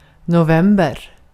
Ääntäminen
IPA: /ˈmɑ.rːɑsˌkuː/